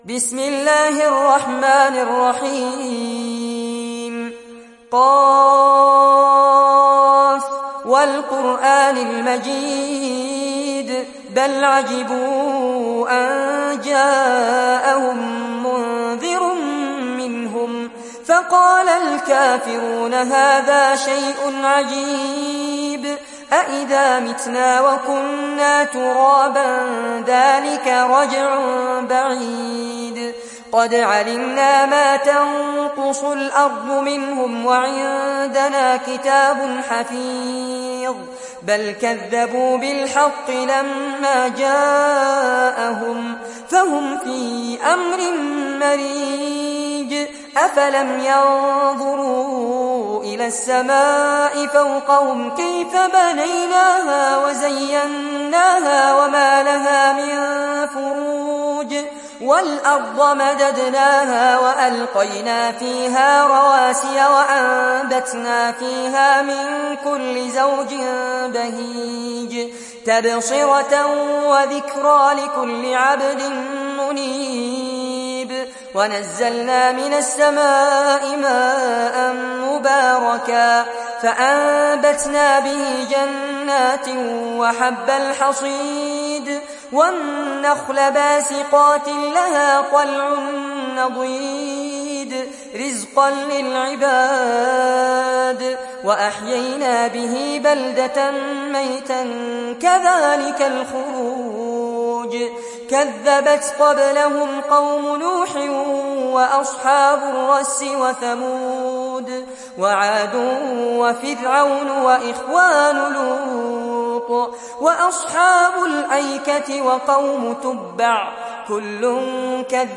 تحميل سورة ق mp3 بصوت فارس عباد برواية حفص عن عاصم, تحميل استماع القرآن الكريم على الجوال mp3 كاملا بروابط مباشرة وسريعة